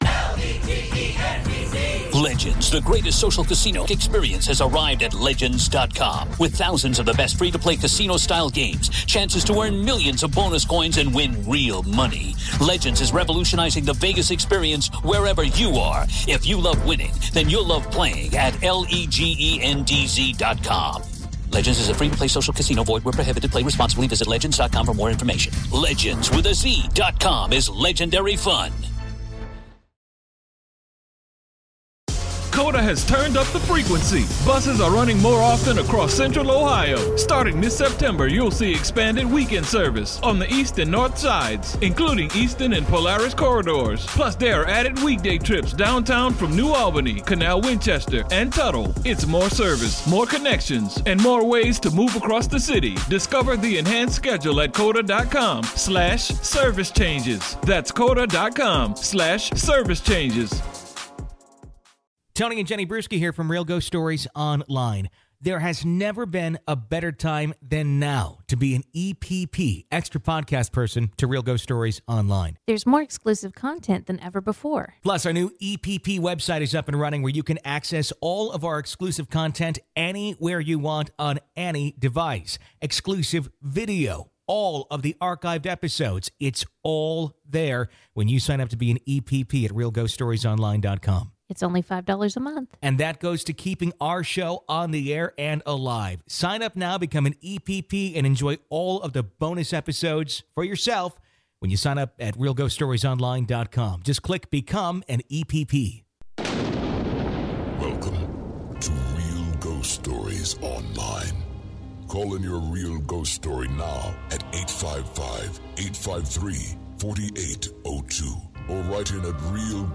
On today's episode we hear stories about demons behind doors. We take your calls about your real ghost expereinces and huantings.